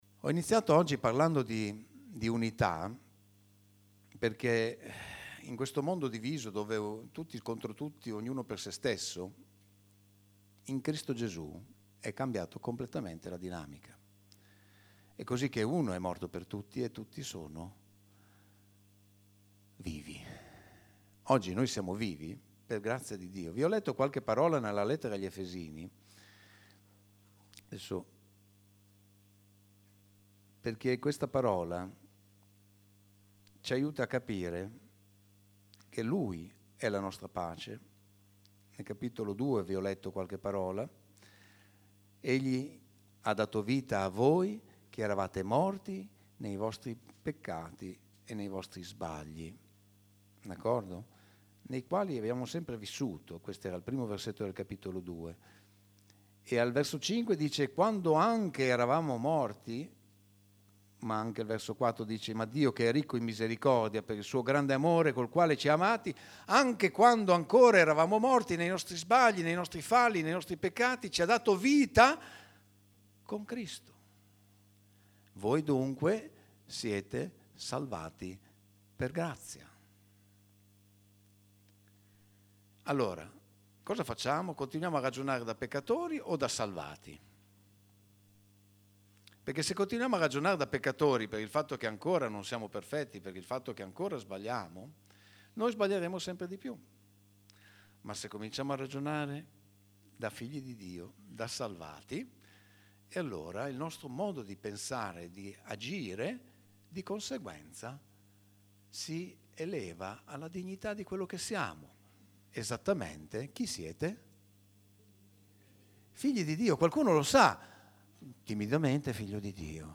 messaggio di questa domenica